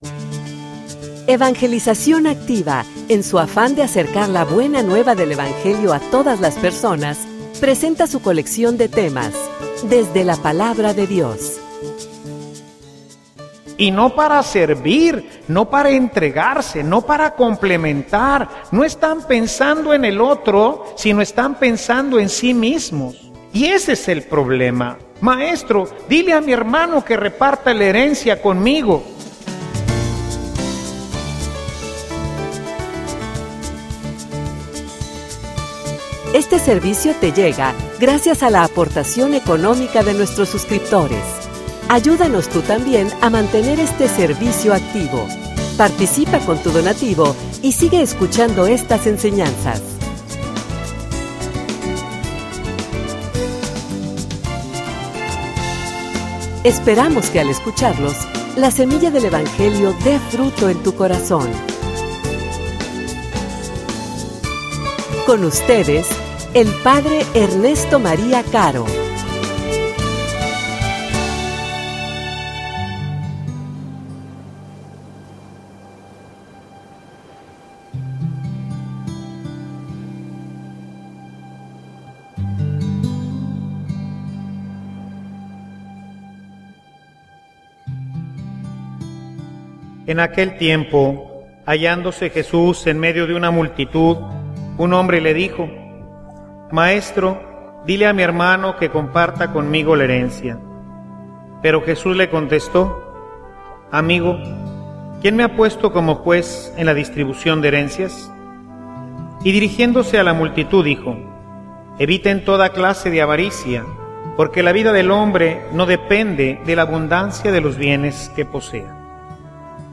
homilia_Que_tu_actuar_trascienda.mp3